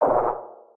Sfx_creature_penguin_hop_voice_01.ogg